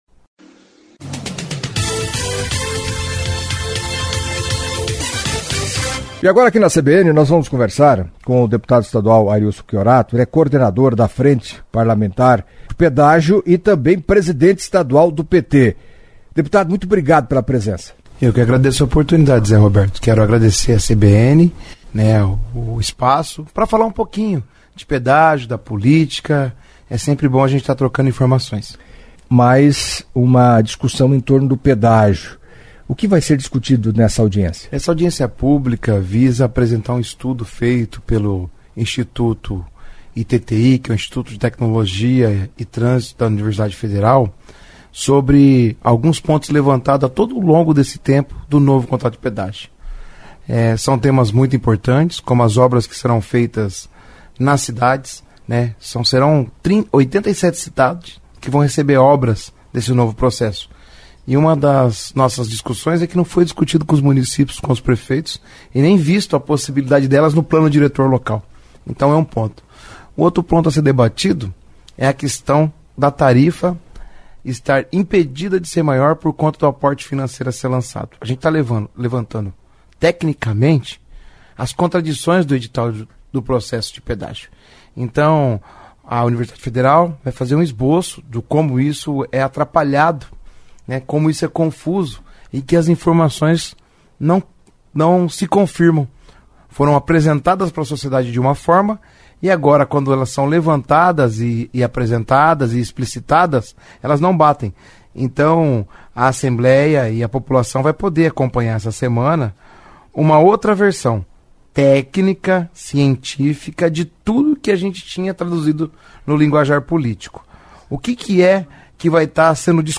Em entrevista à CBN Cascavel nesta sexta-feira (08) o deputado estadual Arilson Chiorato, coordenador da Frente Parlamentar sobre o Pedágio da Assembleia Legislativa e presidente do PT no Paraná, falou da audiência pública programada para a próxima quarta-feira (13) e das eleições de outubro.